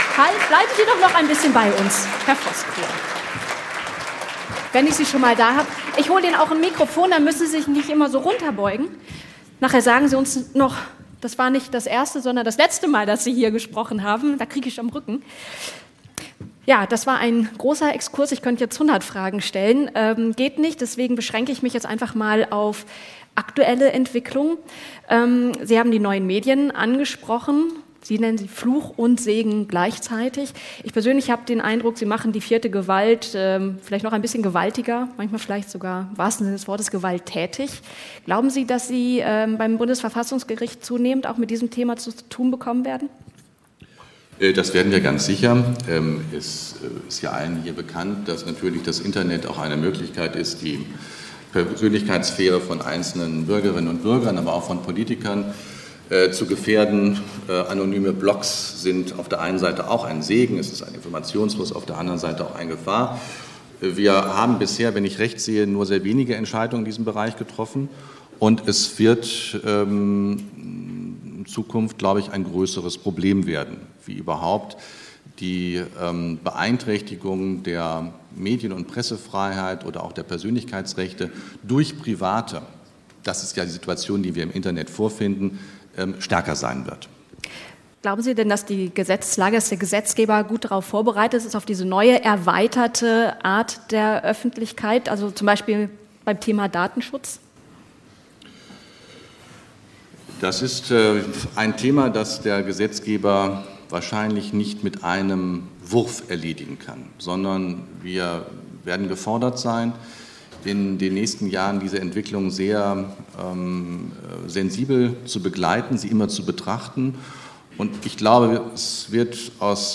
* Prof. Dr. Andreas Voßkuhle, Präsident des Bundesverfassungsgerichts
Was: Interview zur Festrede
Wo: Berlin, Hotel Pullmann Berlin, Schweizerhof, Budapester Straße 25